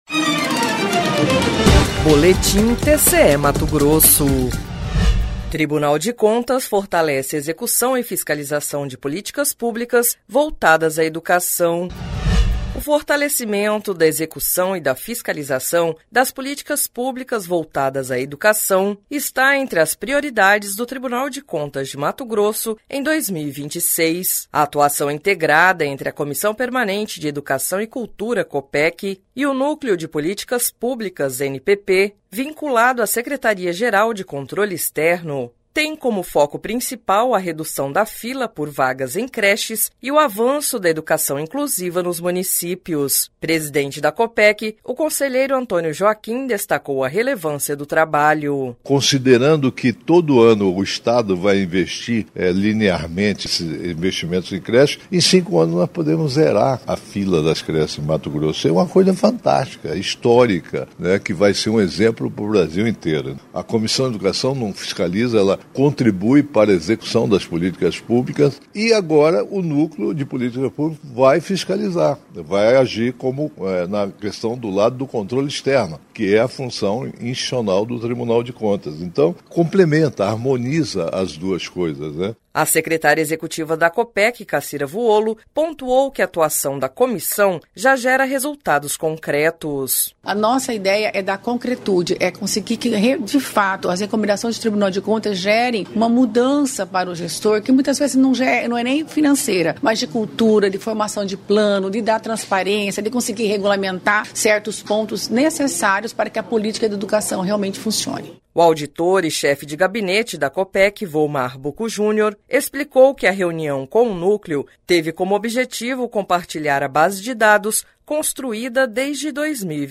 Sonora: Antonio Joaquim – conselheiro presidente da COPEC